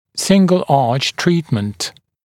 [‘sɪŋgl-ɑːʧ ‘triːtmənt][‘сингл-а:ч ‘три:тмэнт]лечение одного зубной дуги